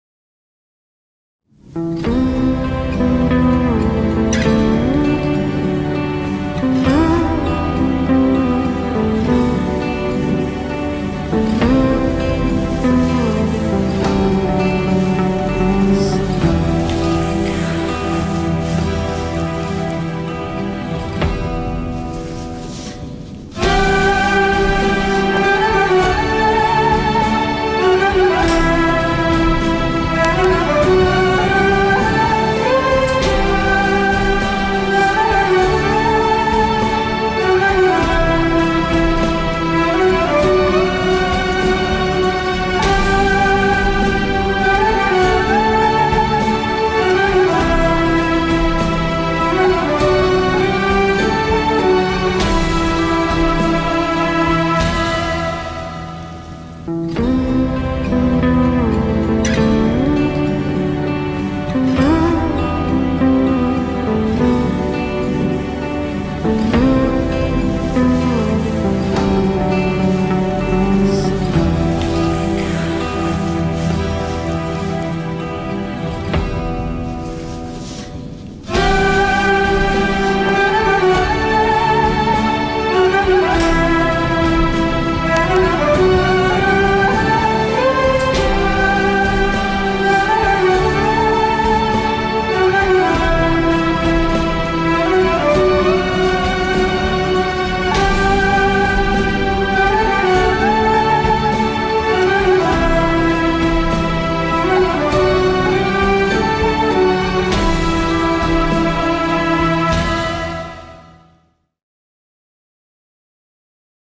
tema dizi müziği, duygusal mutlu huzurlu fon müziği.